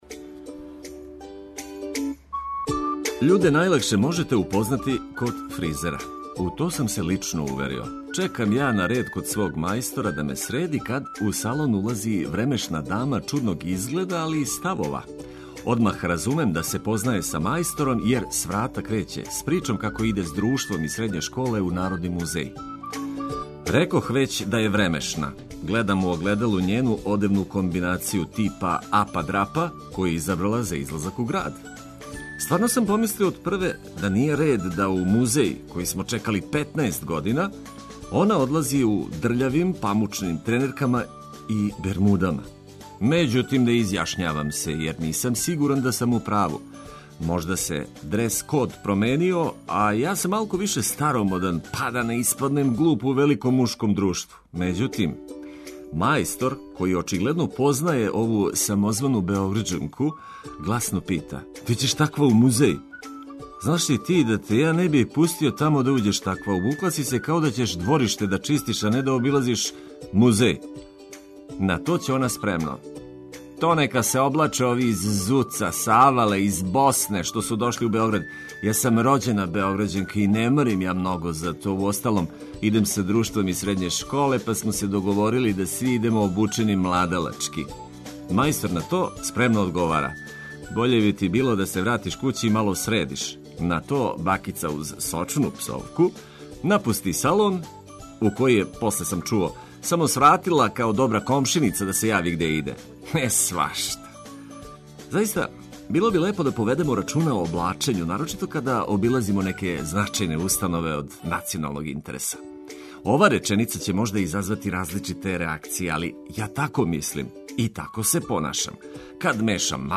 Ведра музика и само важне објаве.